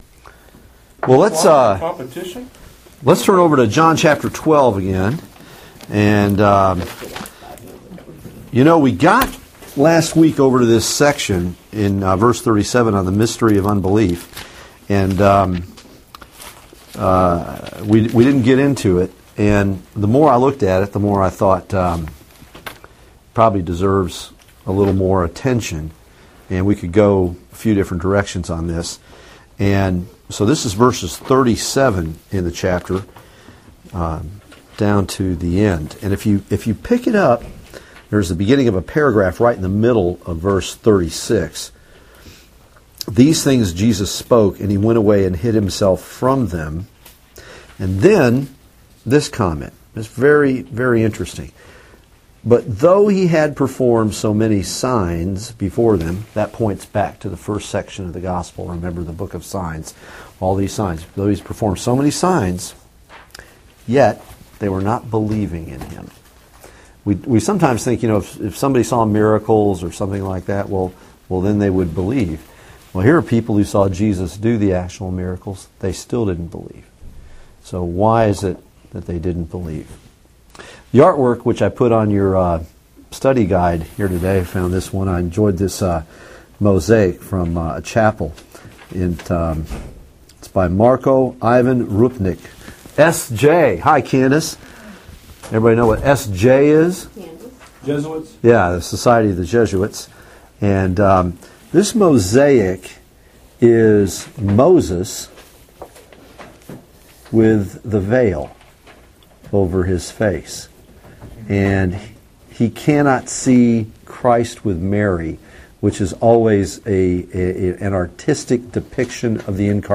Passage: John 12:37-50 Service Type: Bible Study